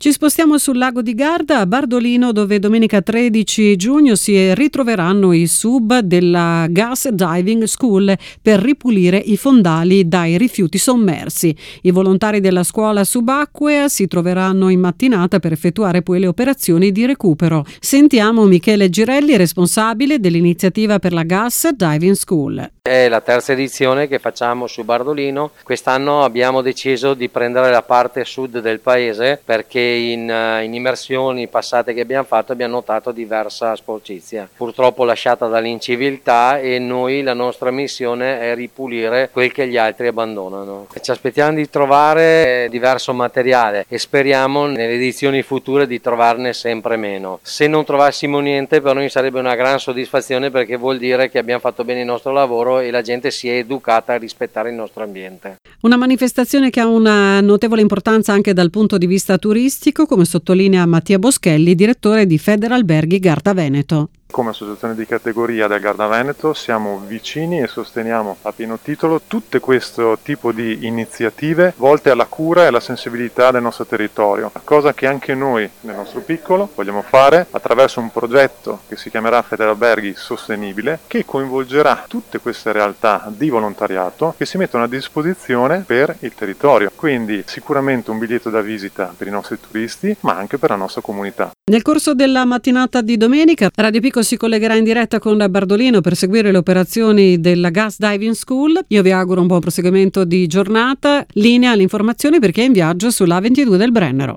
11/06: giornata di pulizia dei fondali del Lago di Garda domenica 13 giugno. La nostra intervista